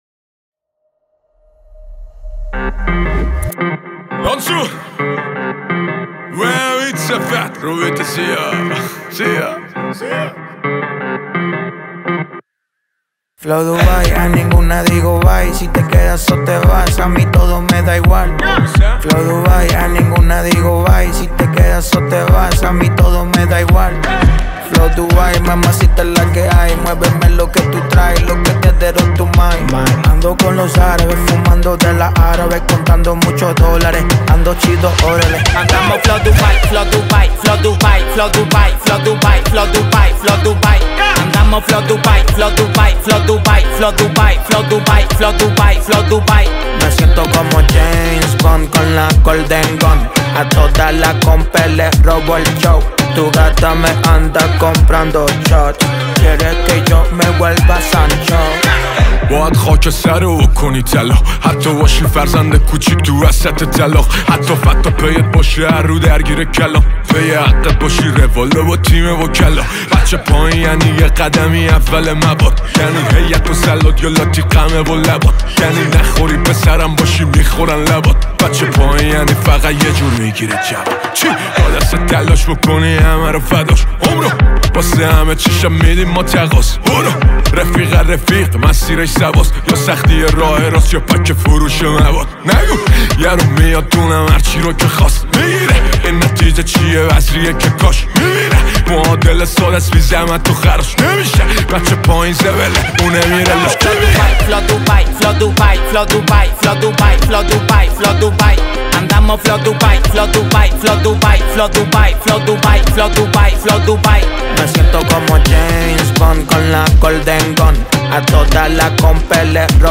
2. موزیک لاتی